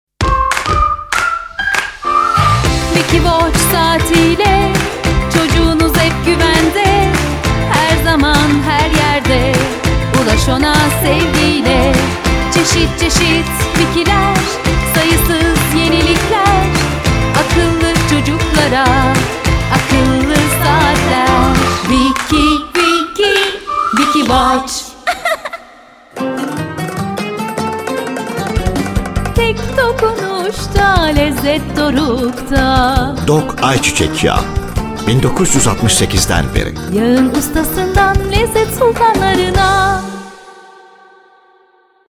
Jingle Advertisement